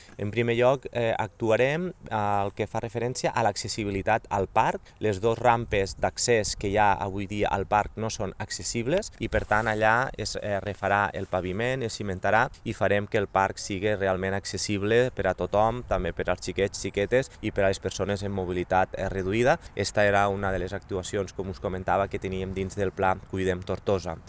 Durant l’execució de les obres, s’haurà de tancar l’accés a l’skatepark per garantir la seguretat dels usuaris. Jordan ha insistit que cal actuar al parc perquè sigui segur i perquè totes les persones puguin gaudir-lo.